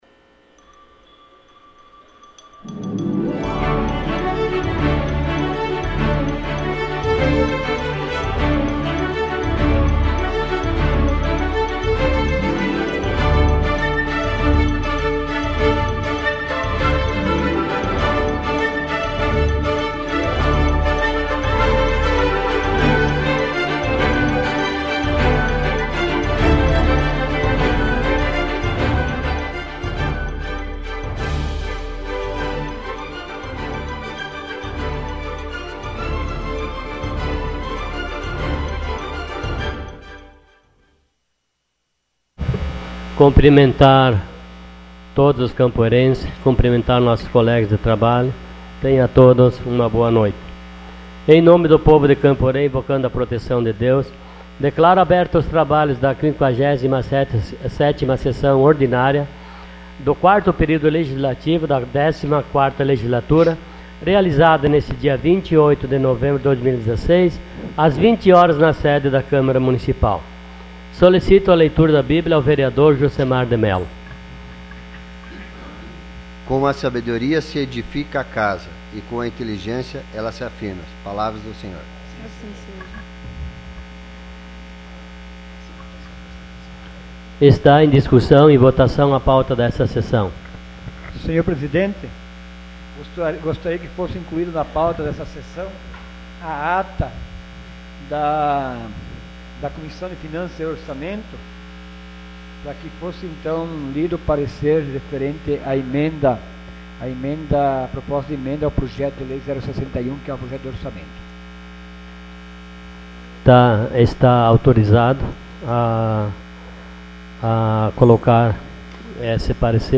Sessão Ordinária dia 28 de novembro de 2016.